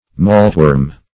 maltworm - definition of maltworm - synonyms, pronunciation, spelling from Free Dictionary Search Result for " maltworm" : The Collaborative International Dictionary of English v.0.48: maltworm \malt"worm`\, n. A tippler.